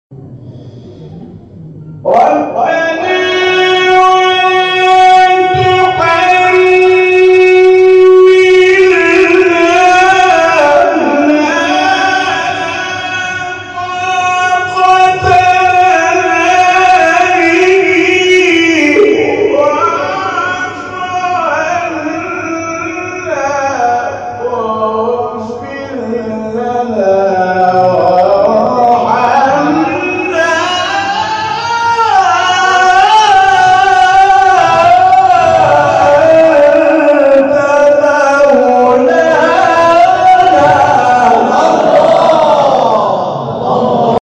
گروه شبکه اجتماعی: نغمات صوتی از تلاوت قاریان ممتاز و بین‌المللی کشور را می‌شنوید.